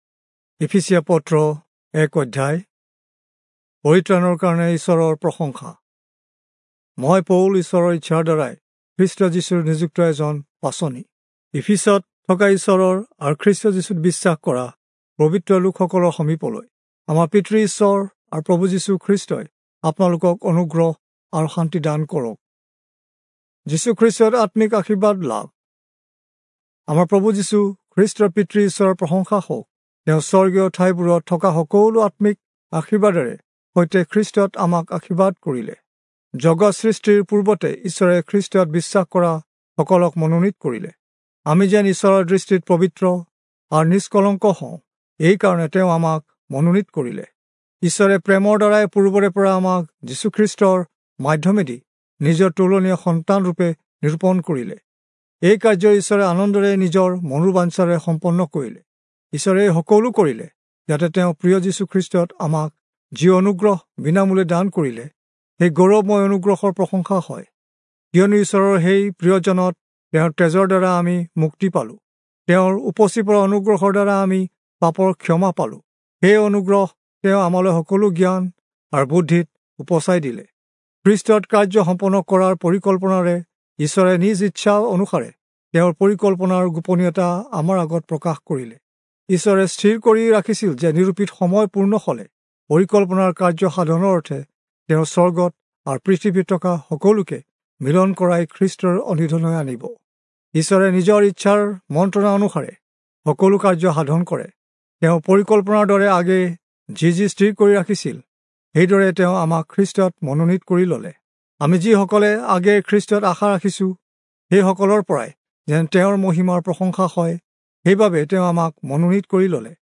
Assamese Audio Bible - Ephesians 5 in Irvpa bible version